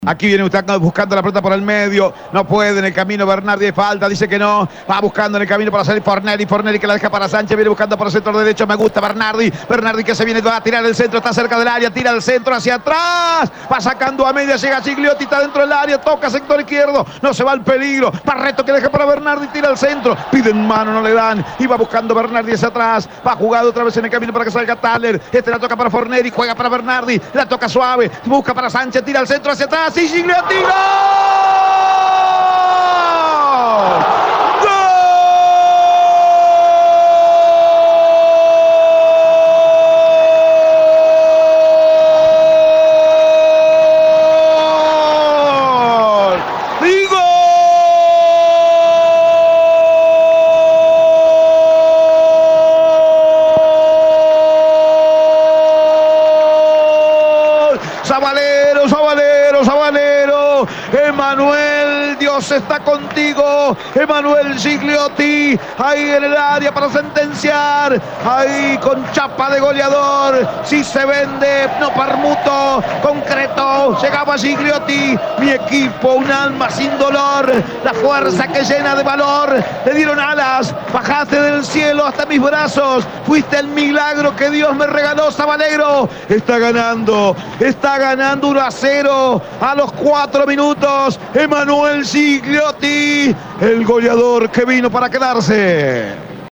LOS GOLES DE COLÓN, EN EL RELATO
01-GOL-COLON.mp3